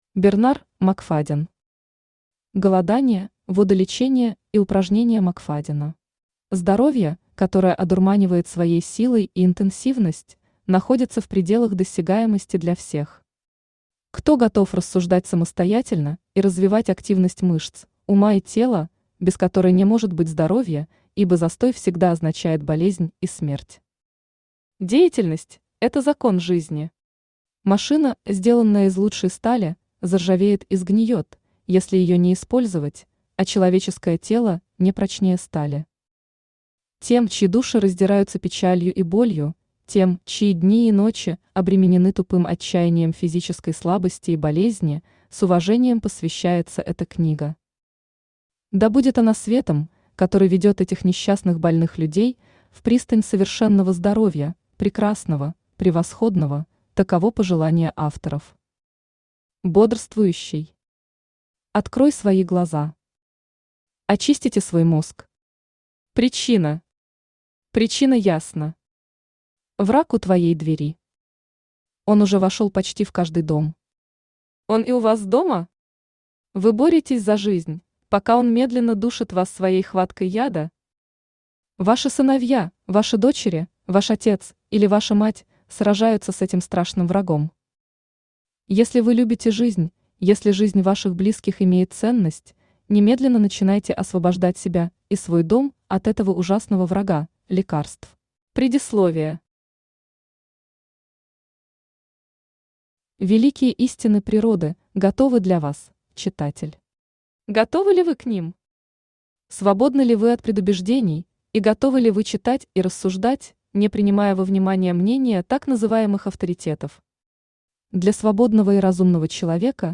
Аудиокнига Голодание, водолечение и упражнения Макфаддена | Библиотека аудиокниг
Aудиокнига Голодание, водолечение и упражнения Макфаддена Автор Бернар Макфадден Читает аудиокнигу Авточтец ЛитРес.